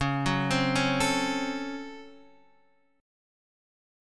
C#M7sus4#5 chord